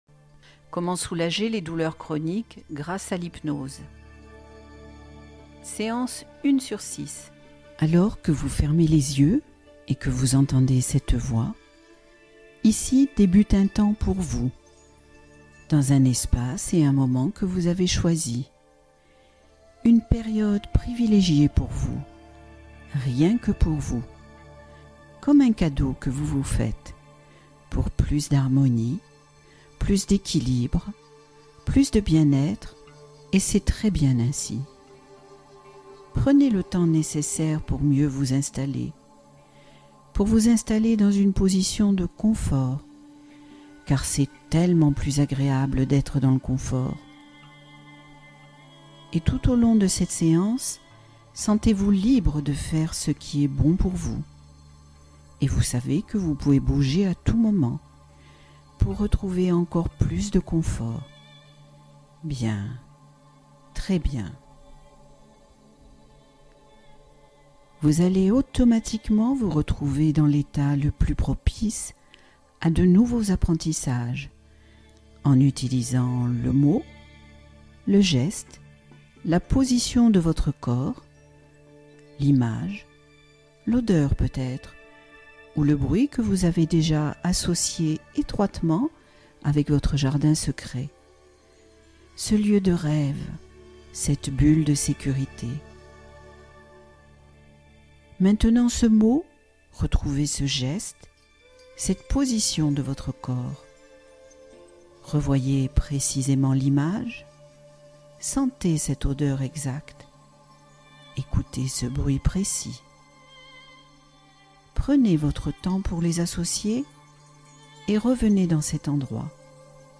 Hypnose arrêt du tabac : programme en 6 séances